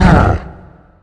spawners_mobs_balrog_hit.4.ogg